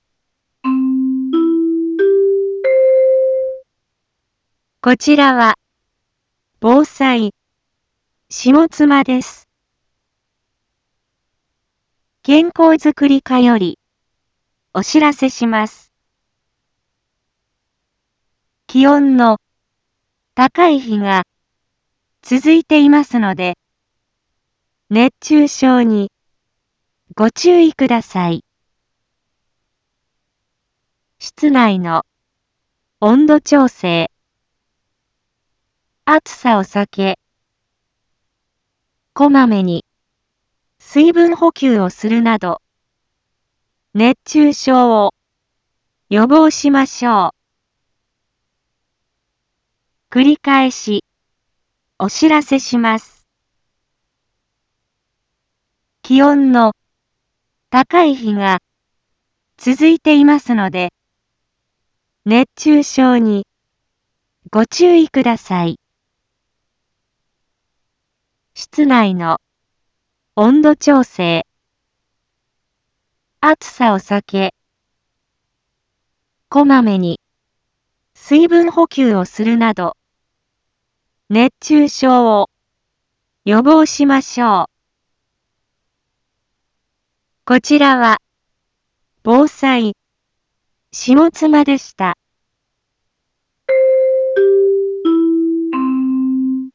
一般放送情報
Back Home 一般放送情報 音声放送 再生 一般放送情報 登録日時：2024-07-22 11:01:40 タイトル：熱中症注意のお知らせ インフォメーション：こちらは、ぼうさい、しもつまです。